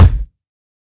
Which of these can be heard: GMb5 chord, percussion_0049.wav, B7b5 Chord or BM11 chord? percussion_0049.wav